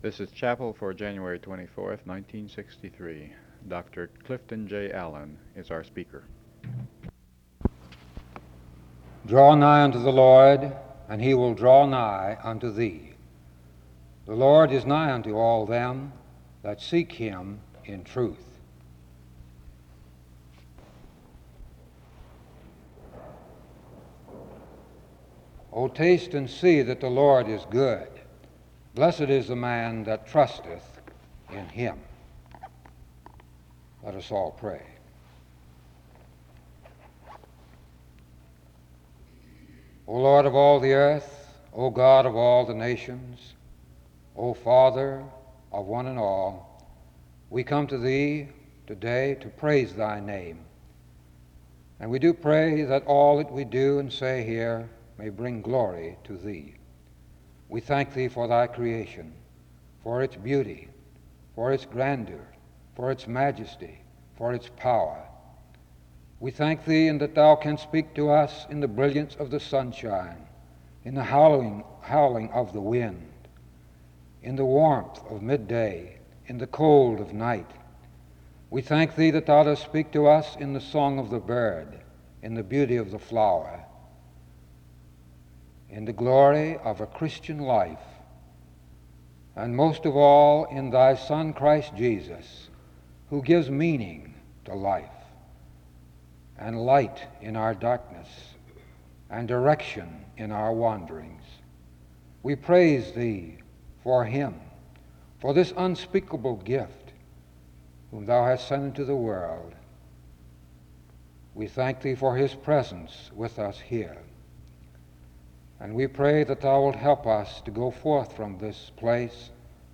The service begins with prayer from 0:00-4:13.
SEBTS Chapel and Special Event Recordings SEBTS Chapel and Special Event Recordings